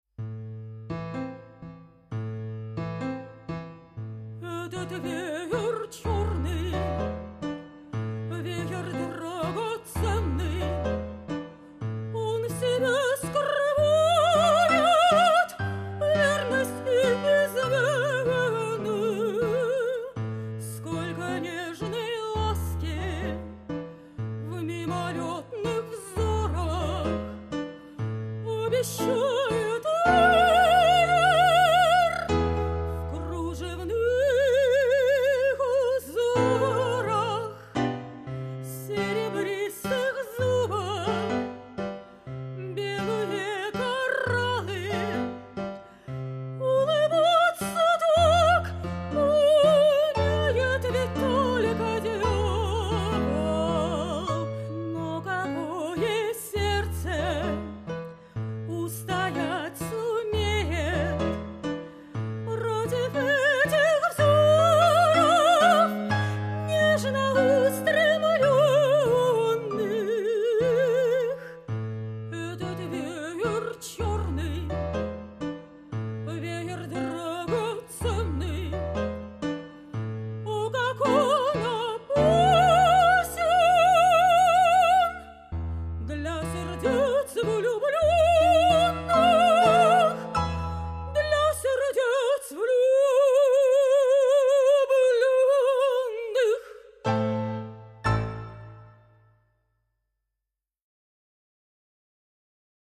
Музыка П.Сарасате, русский текст Г.Гнесина
меццо-сопрано